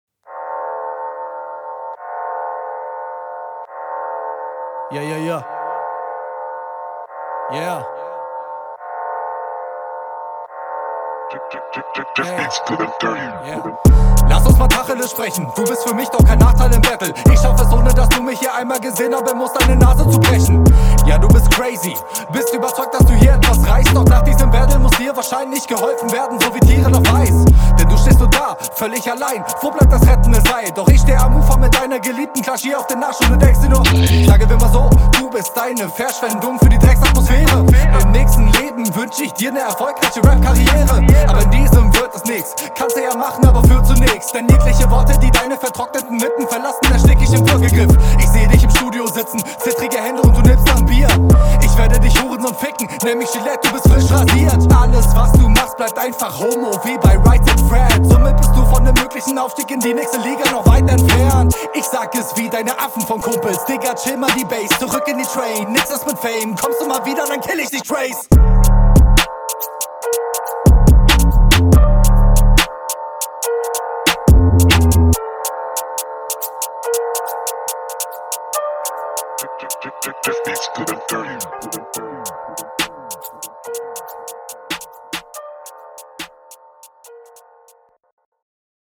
Beat steht dir sehr gut.